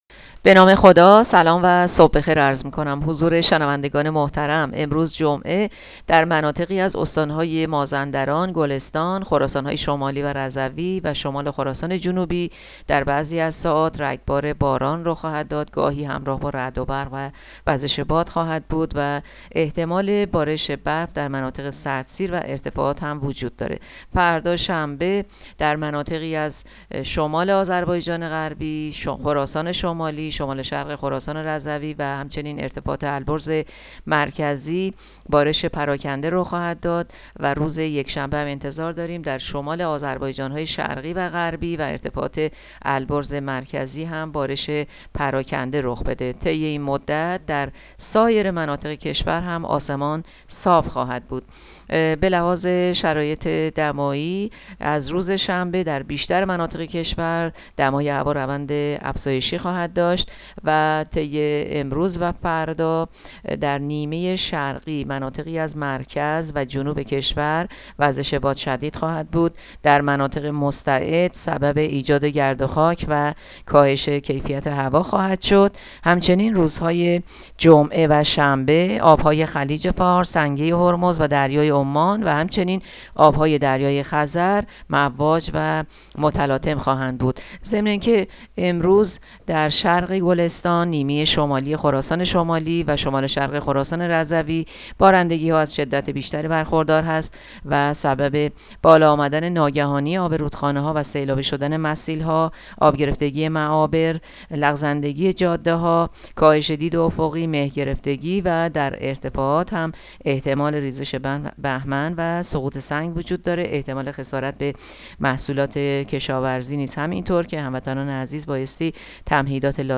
گزارش رادیو اینترنتی پایگاه‌ خبری از آخرین وضعیت آب‌وهوای ۲۹ فروردین؛